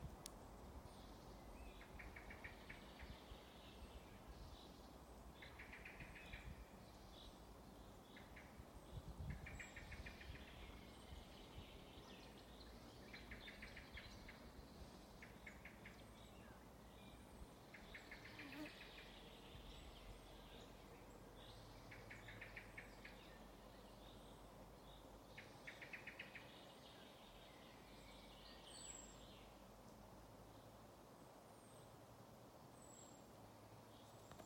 Putni -> Mežastrazdi ->
Melnais mežastrazds, Turdus merula
StatussUzturas ligzdošanai piemērotā biotopā (B)